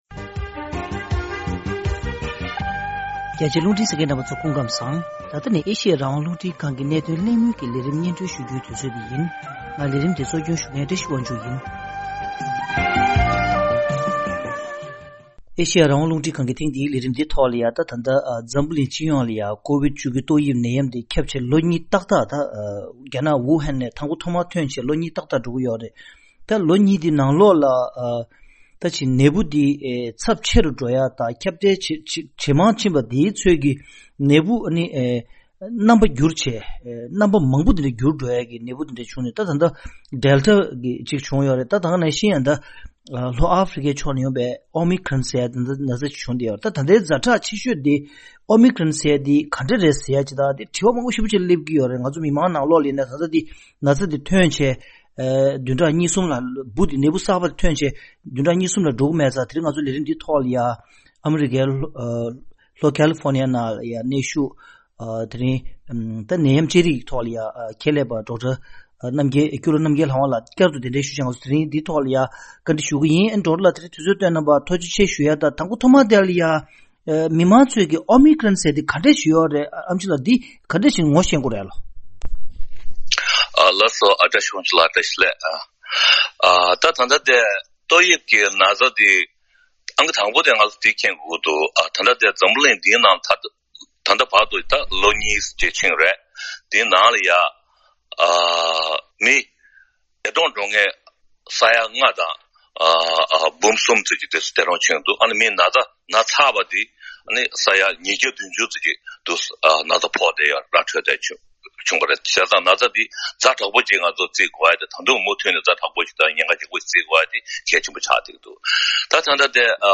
གླེང་མོལ་ཞིབ་རྒྱས་ཞུས་པ་ཞིག་གསན་རོགས་ཞུ།